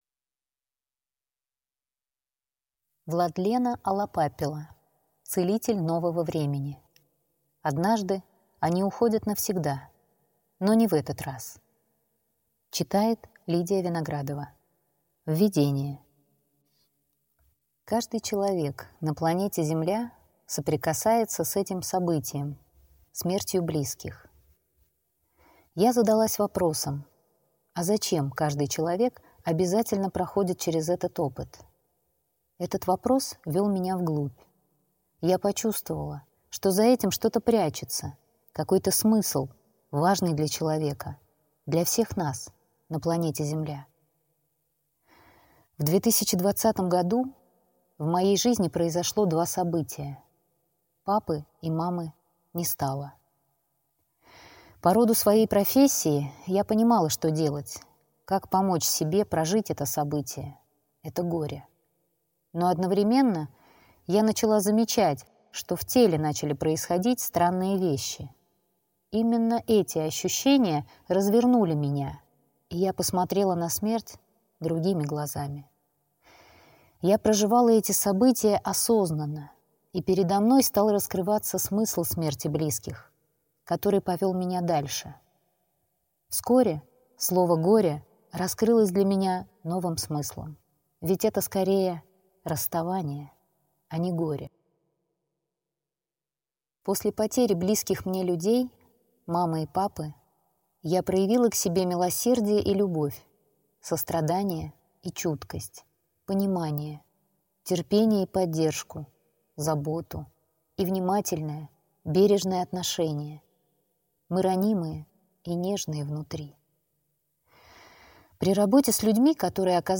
Аудиокнига Однажды они уходят навсегда, но не в этот раз | Библиотека аудиокниг